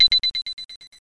download-complete.mp3